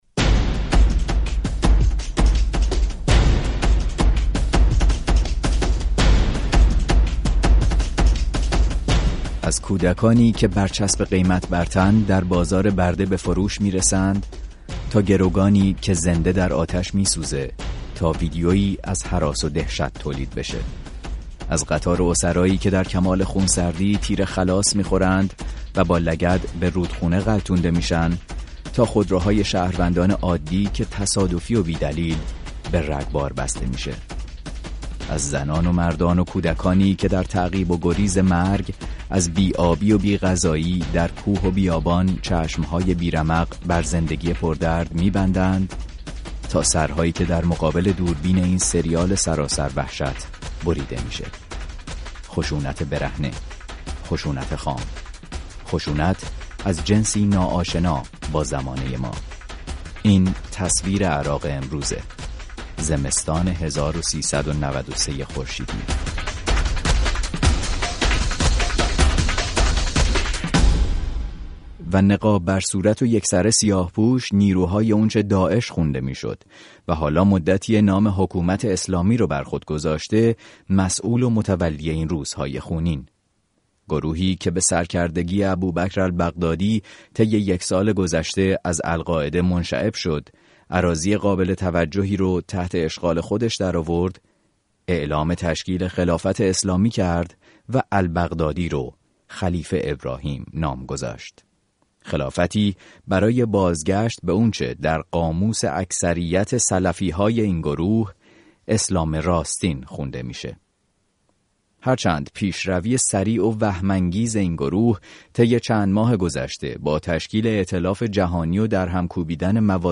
برنامه «ساعت ششم» میزبان مخاطبان رادیو فردا